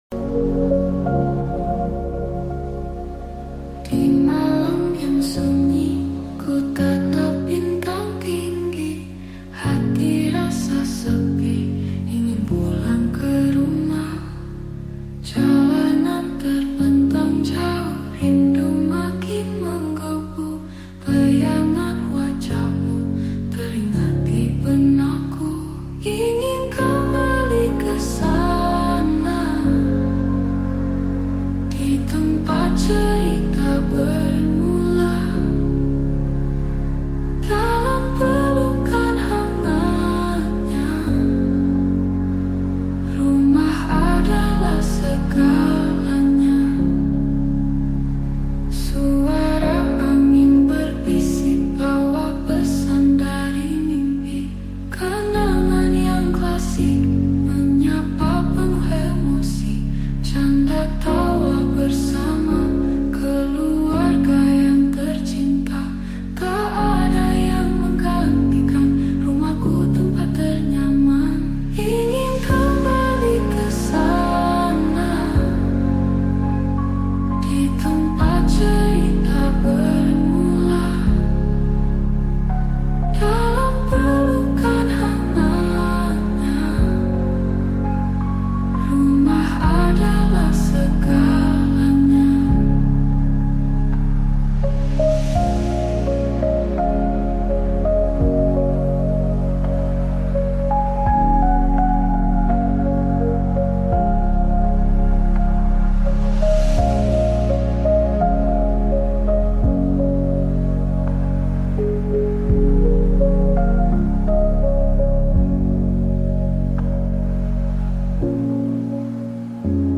ambience of home 8d audio#8daudio sound effects free download